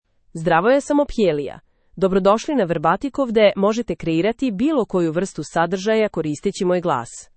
Ophelia — Female Serbian AI voice
Ophelia is a female AI voice for Serbian (Serbia).
Voice sample
Listen to Ophelia's female Serbian voice.
Female